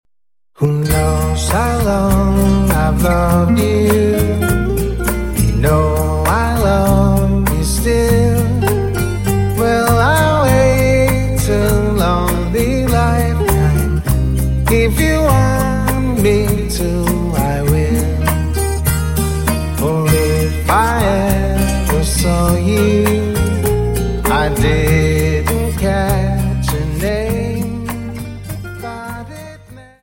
Dance: Rumba Song